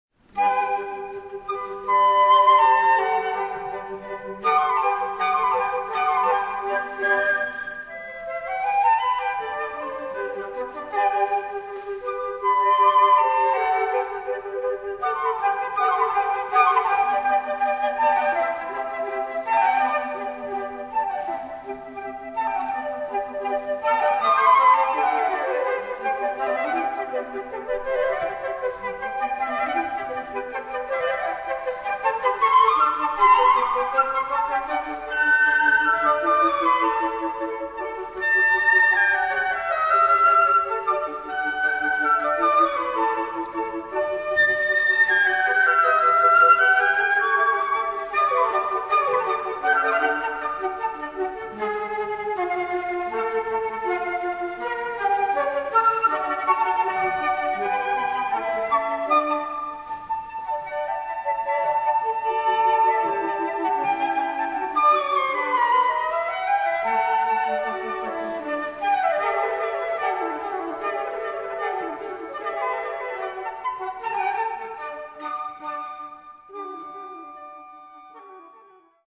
Transkription für 4 Flöten / (4. Flöte Altflöte in G)
(3 flutes alto flute)
Category: Four Flutes